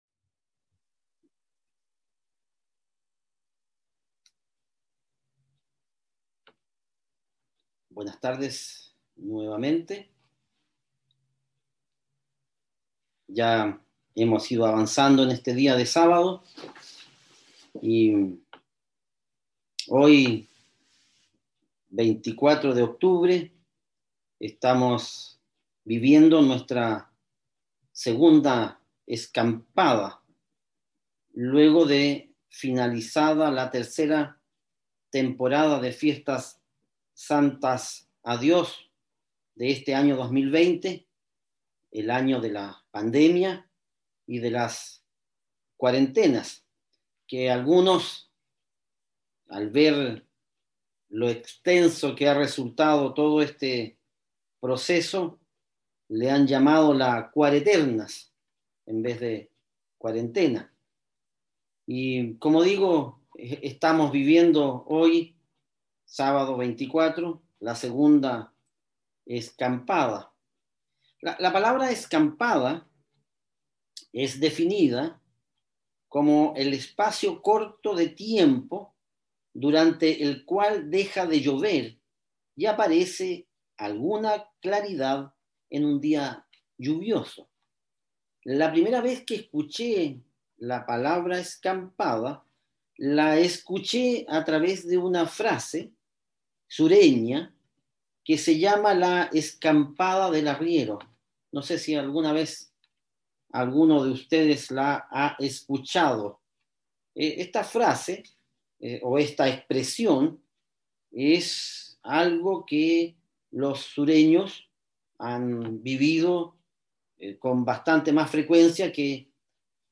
Después de celebrar la segunda temporada de Fiestas Santas en un año tan caótico, tenemos un remanso de paz que podemos aprovechar para visualizar lo que está por venir. Mensaje entregado el 24 de octubre de 2020.